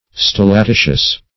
Search Result for " stillatitious" : The Collaborative International Dictionary of English v.0.48: Stillatitious \Stil`la*ti"tious\, a. [L. stillaticius, fr. stillare to drop, stilla a drop.]